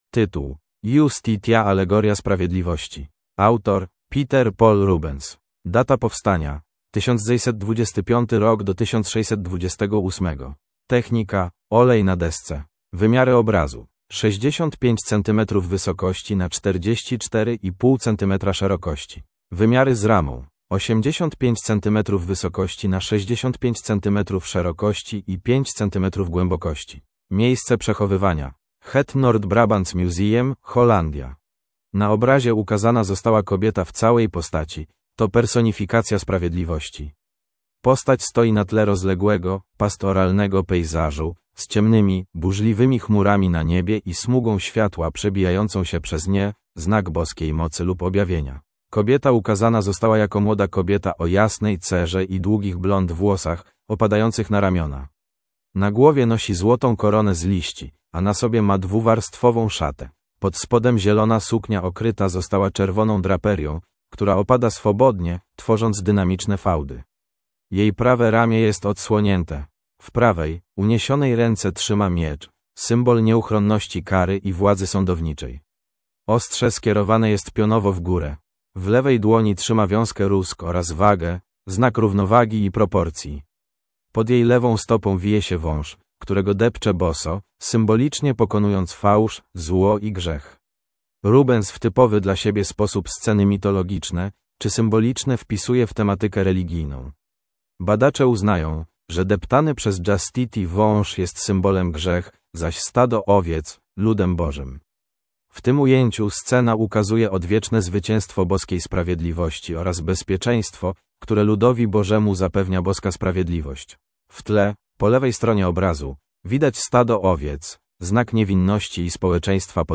MNWr_audiodeskr_Justitia_Alegoria_Sprawiedliwosci.mp3